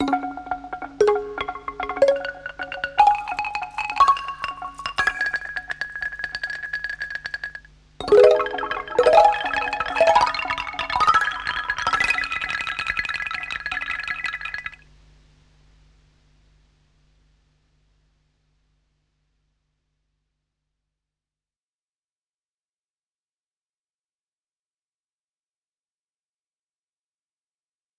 它具有有机的声音，带有许多小的不完美之处。